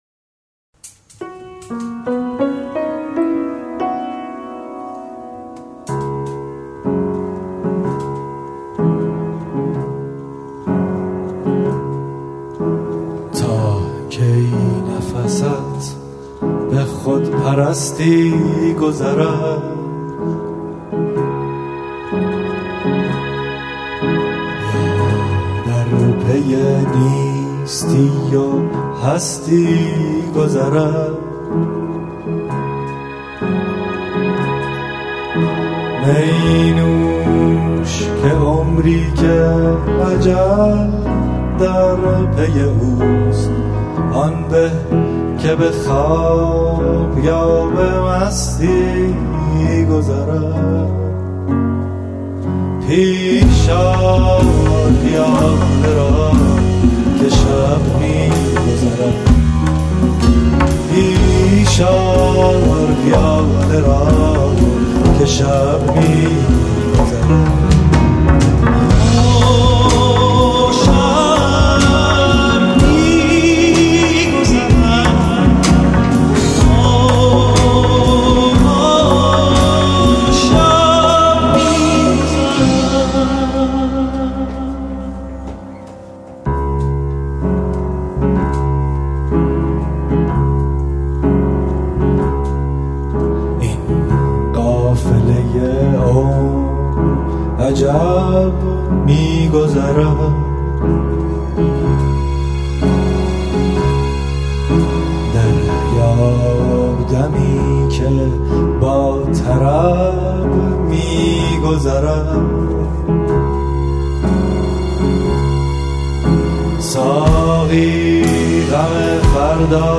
سنتی
• آهنگ سنتی